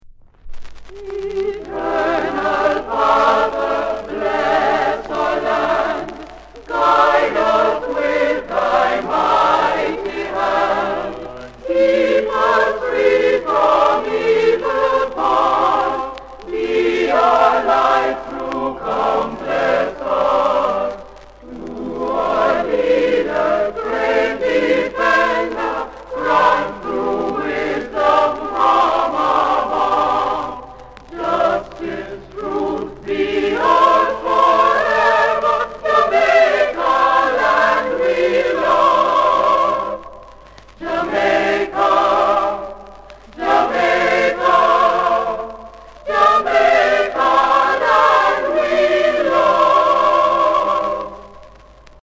A SIDE - INST B SIDE - VOCAL
light warp